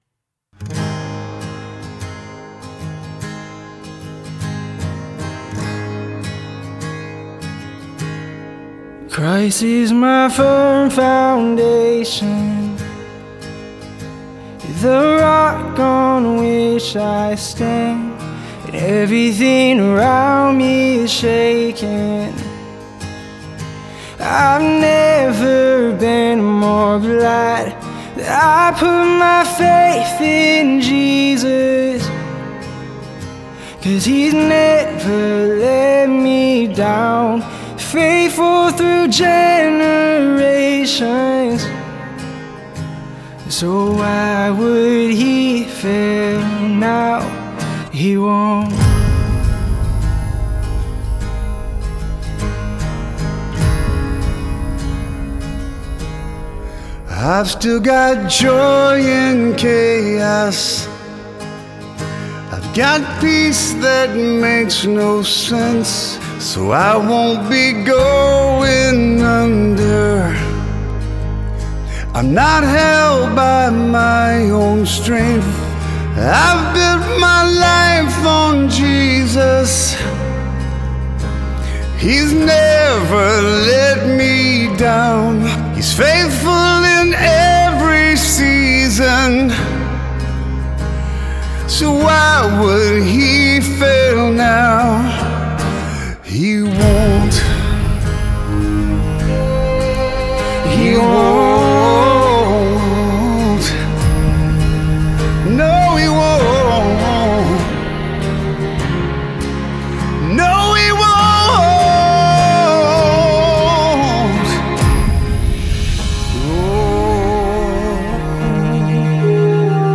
and her husband flew up to Nashville to record a song with Phil Vassar as a gift to her